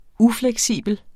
Udtale [ ˈuflεgˌsiˀbəl ]